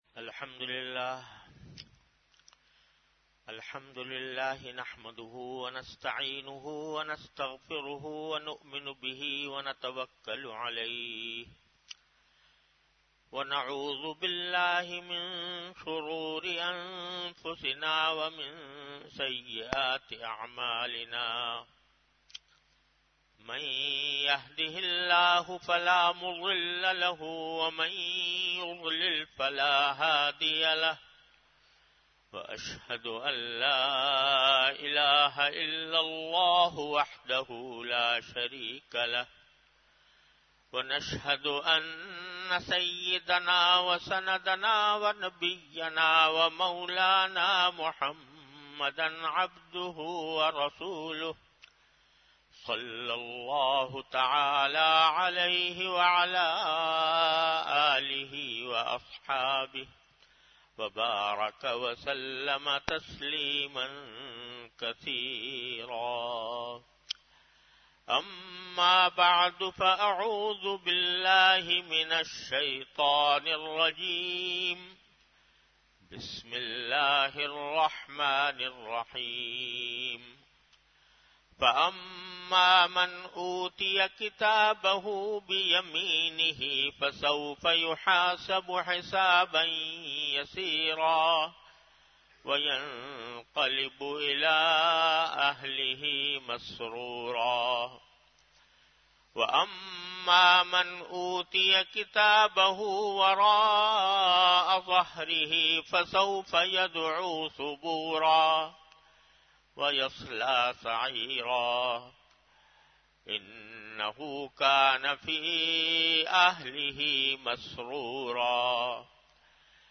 An Islamic audio bayan by Hazrat Mufti Muhammad Taqi Usmani Sahab (Db) on Tafseer. Delivered at Jamia Masjid Bait-ul-Mukkaram, Karachi.